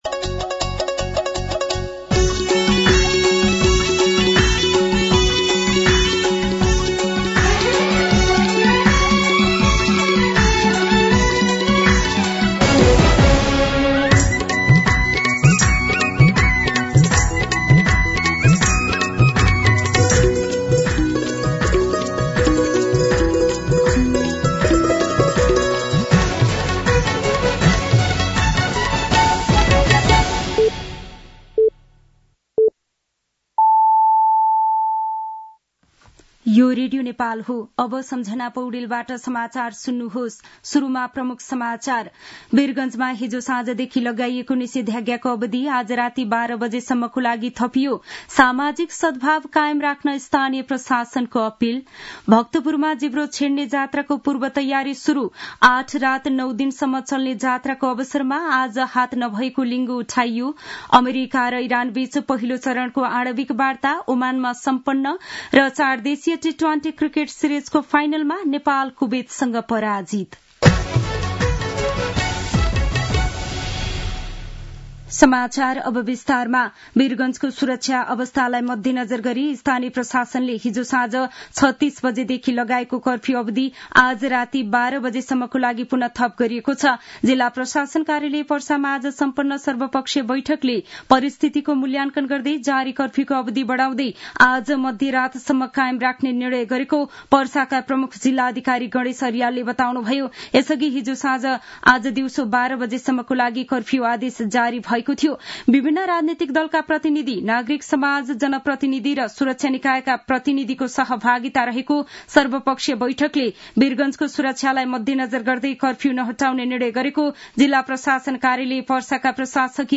दिउँसो ३ बजेको नेपाली समाचार : ३१ चैत , २०८१
3-pm-Nepali-News-4.mp3